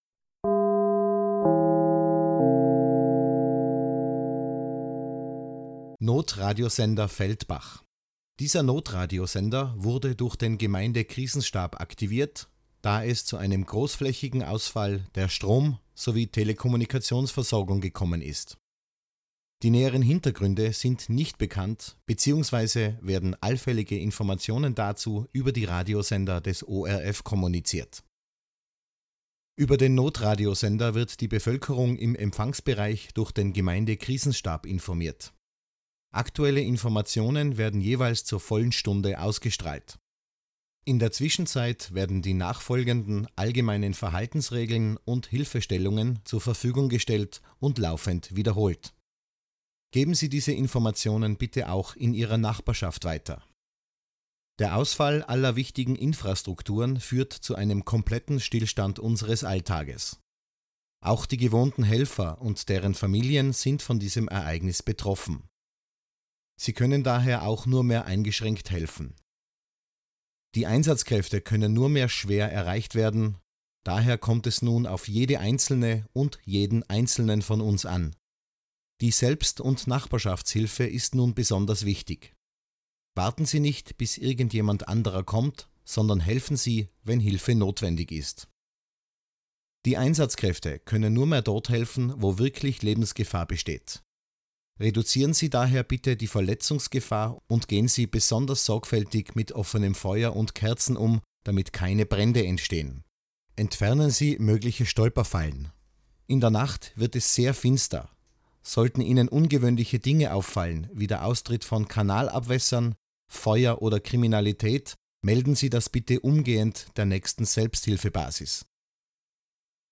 notradiosender-feldbach-schleife.mp3